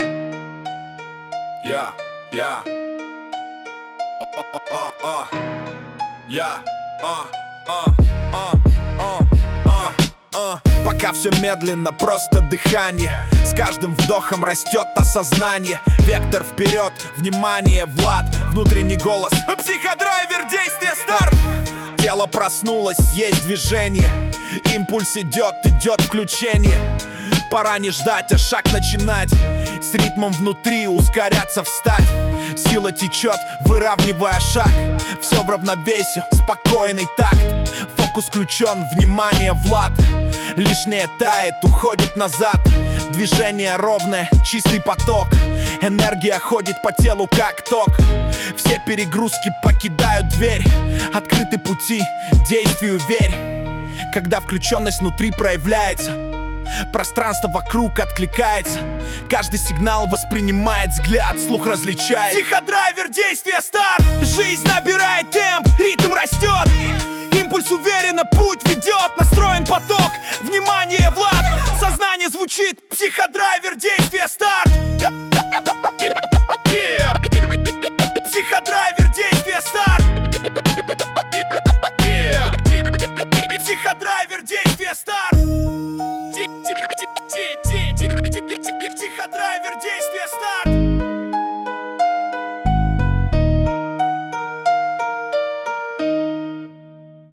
Это РЭП версия , что нравится то и слушайте.